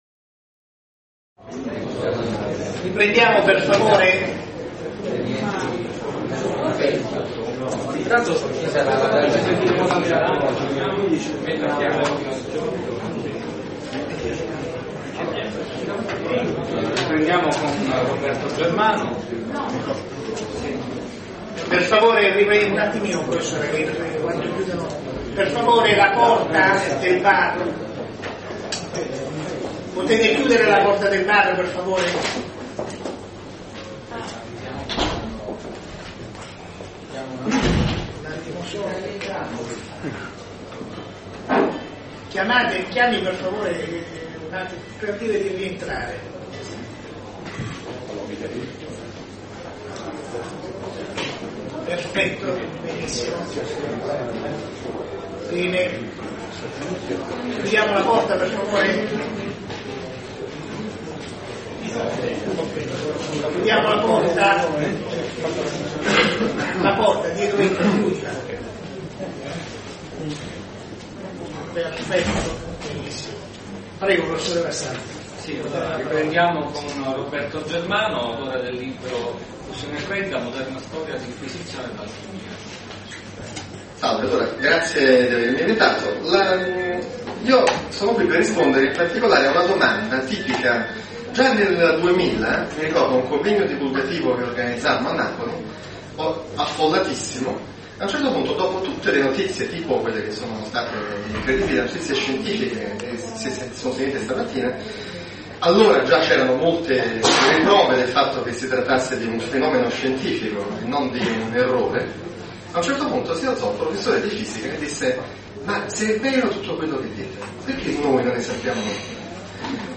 Convegno tenutosi il 2 luglio 2012 a Roma presso la Sala della Mercede della Camera dei Deputati, organizzato dall'Università degli Studi di Palermo.